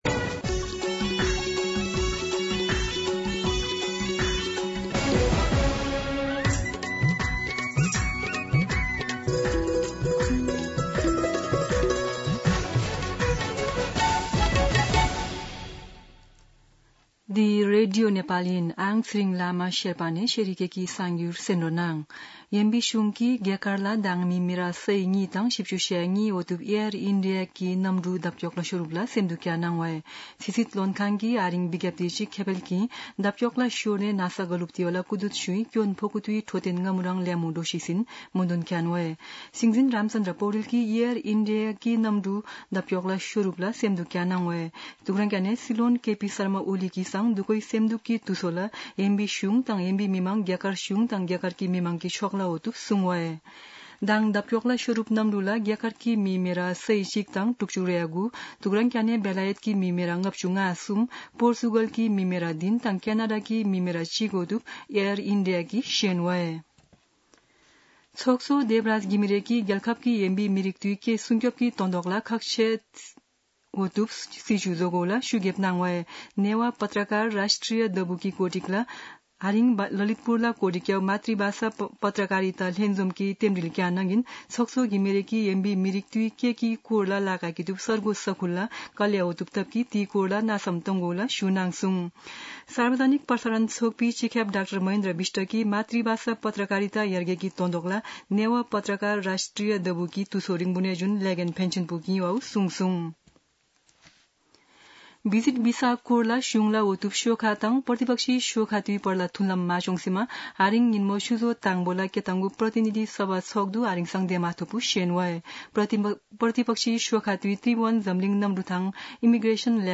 शेर्पा भाषाको समाचार : ३० जेठ , २०८२